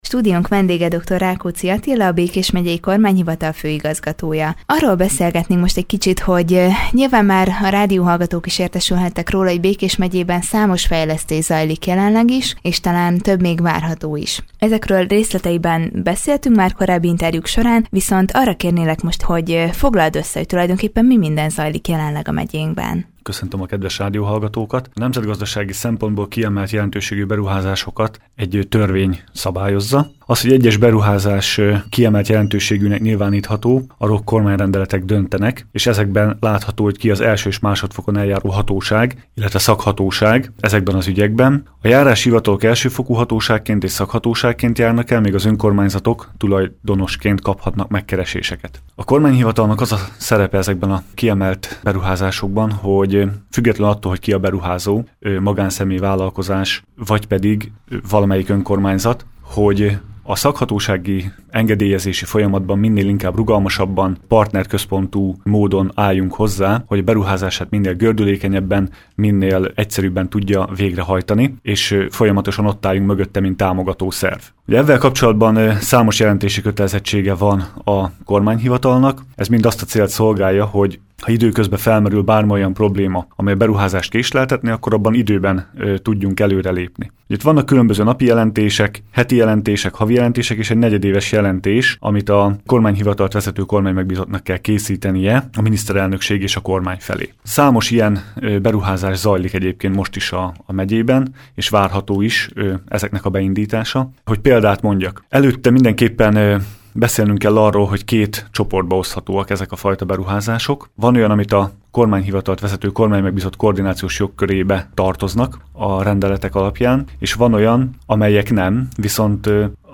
Kiemelt jelentőségű beruházások is zajlanak a megyében, melyek megvalósulását segíti a Kormányhivatal. Ezzel kapcsolatban beszélgetett tudósítónk Dr. Rákóczi Attilával, a Békés Megyei Kormányhivatal főigazgatójával.